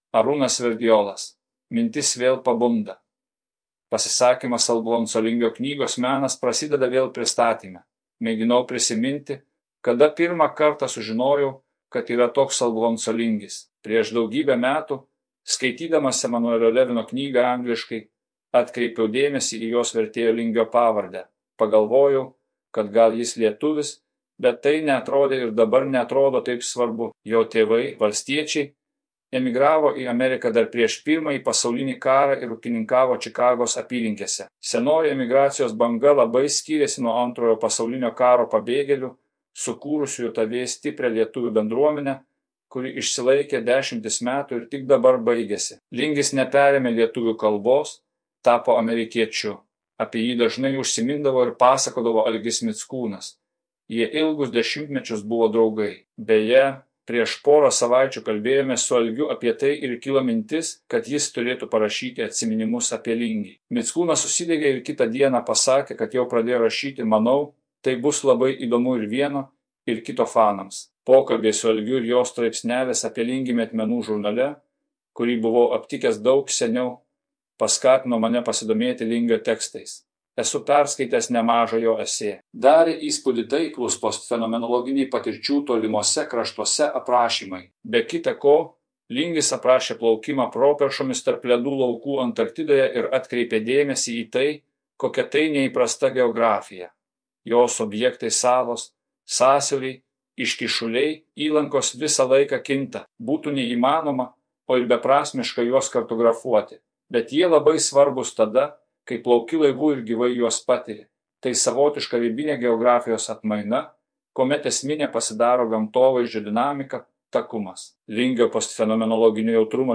Pasisakymas Alphonso Lingio knygos „Menas prasideda vėl“1 pristatyme